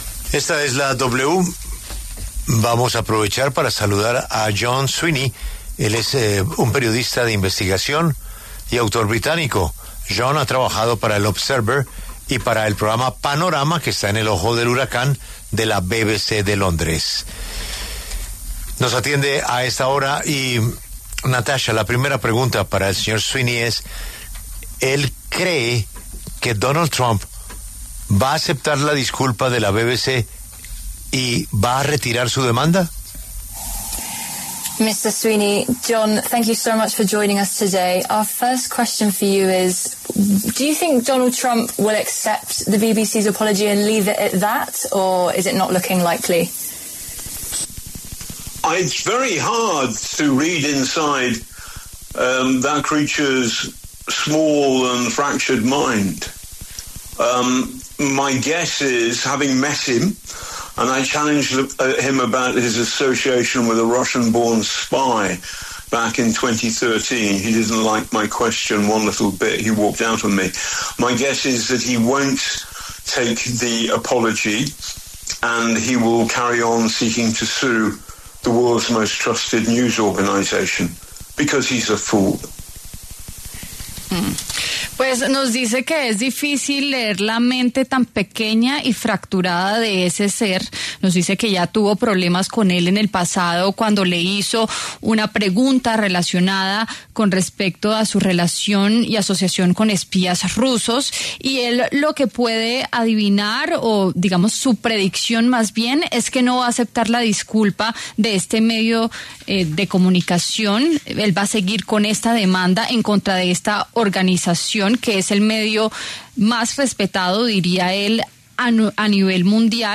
John Sweeney, periodista de investigación, conversó en La W sobre la controversia entre el presidente Donald Trump y la BBC, de Londres, y analizó el caso.